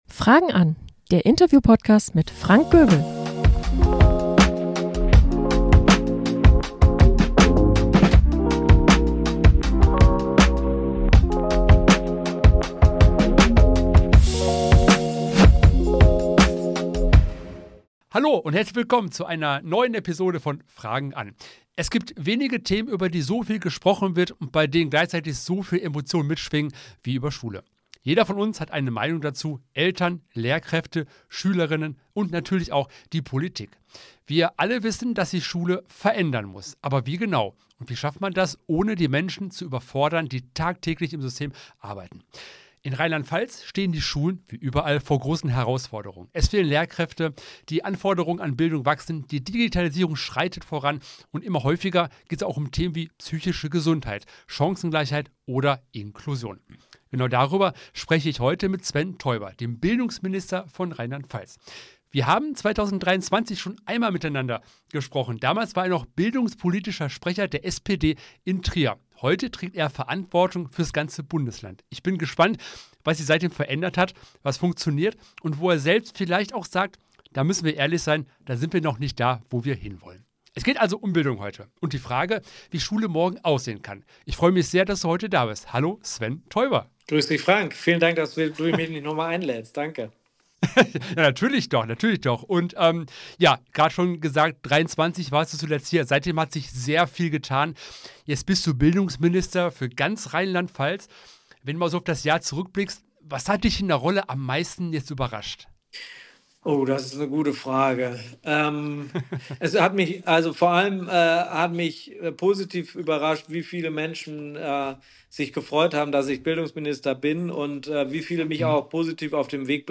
Sven Teuber – Was Schule heute leisten muss In dieser Folge von Fragen an… spreche ich mit Sven Teuber, dem Bildungsminister von Rheinland-Pfalz. Gemeinsam blicken wir auf das deutsche Bildungssystem – auf das, was schon richtig gut läuft, und auf das, was dringend besser werden muss. Sven Teuber spricht offen über Chancen und Herausforderungen an Schulen, über Lehrkräftemangel (den es in RLP nicht gibt), Digitalisierung, Chancengleichheit und die Bedeutung moderner Pädagogik.